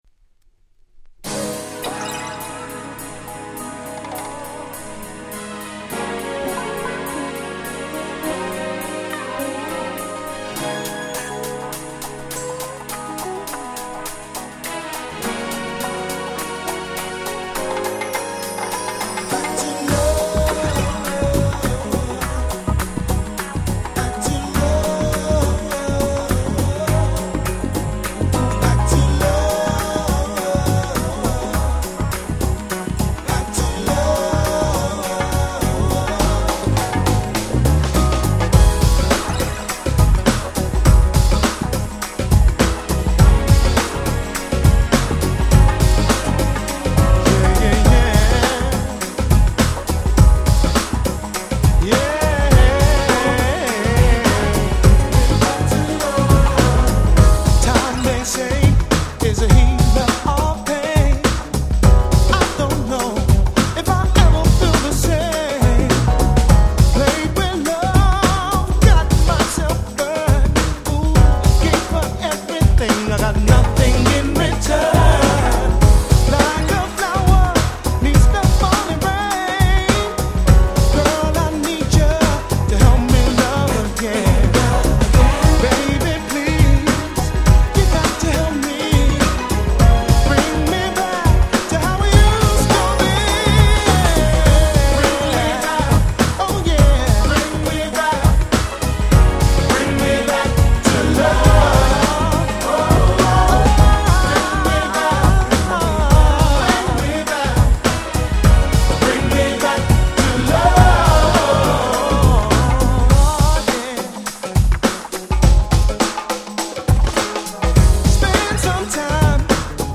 94' Nice UK R&B !!!
爽快なMelodyが心地良すぎる最高のUK R&B !!